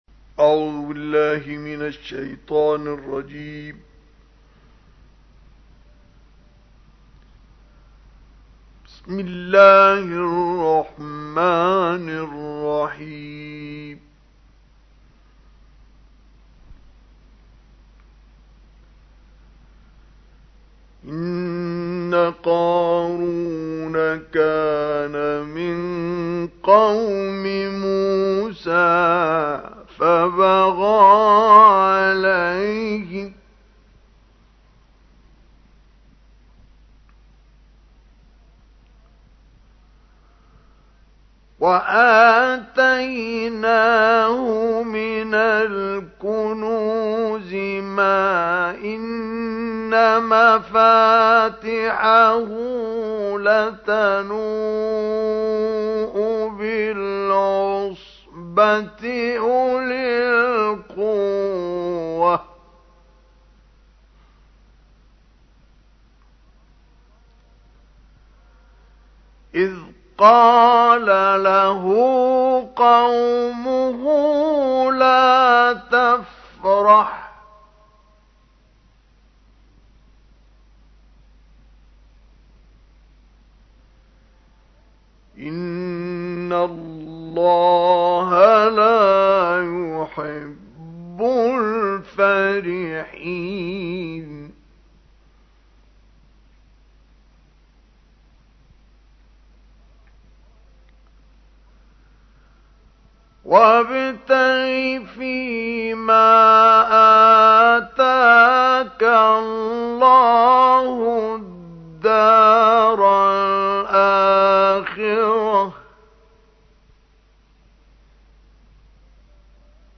تلاوت کمتر شنیده شده از عبدالباسط/ آیاتی از سوره‌های قصص و عنکبوت+صوت
به گزارش خبرنگار قرآن و فعالیت‌های دینی خبرگزاری فارس عبدالباسط محمد عبدالصمد قاری نامدار جهان اسلام متولد ۱۹۲۶ و درگذشته ۱۹۸۸ کشور مصر است.